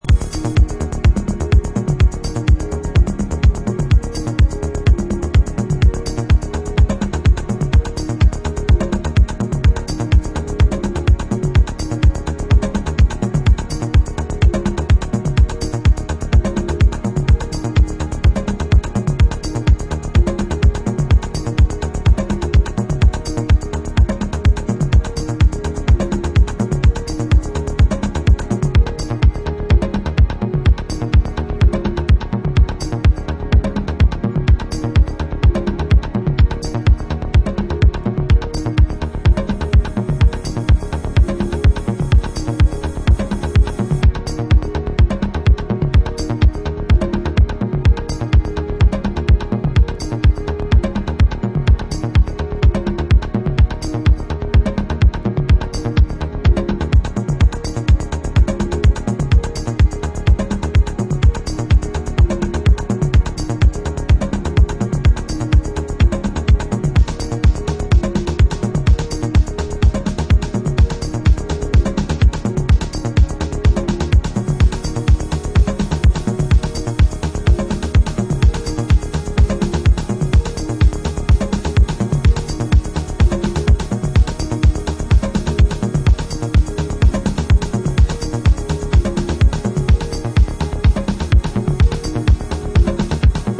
シカゴ・ ハウス注目の次世代気鋭プロデューサー